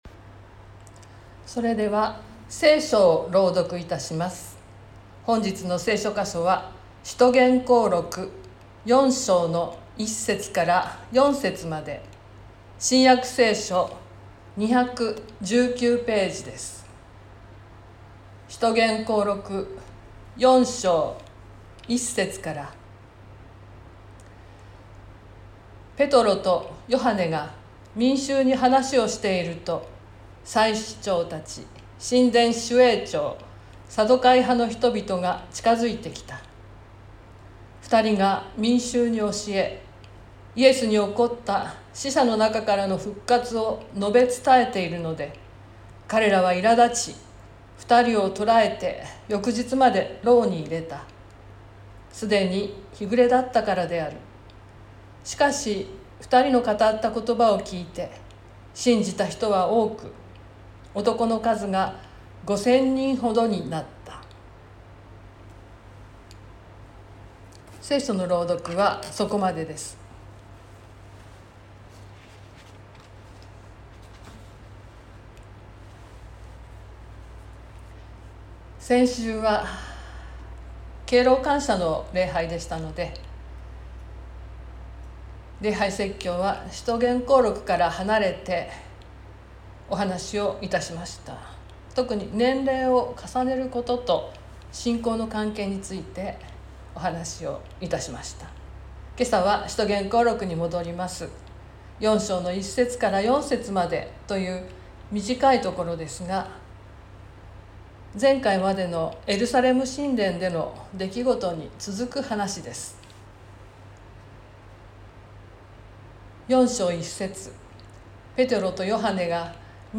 2020年09月20日朝の礼拝「死者の中からの復活」川越教会
説教アーカイブ。
音声ファイル 礼拝説教を録音した音声ファイルを公開しています。